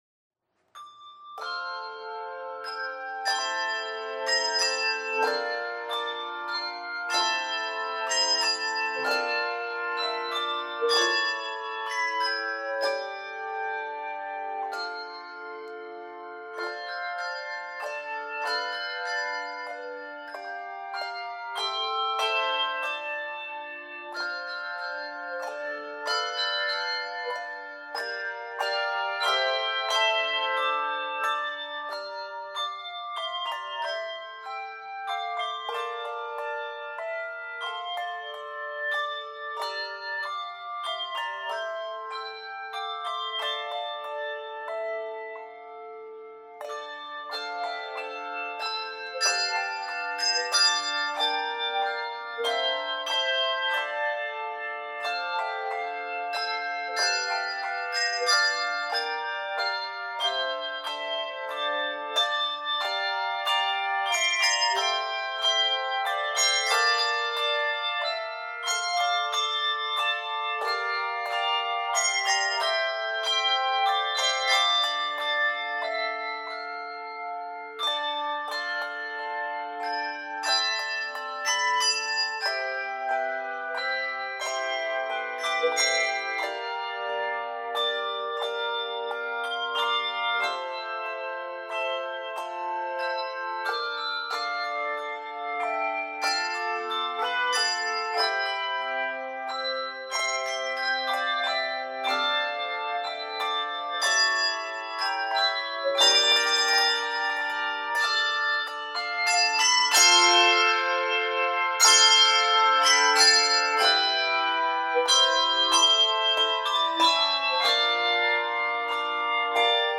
Key of d minor.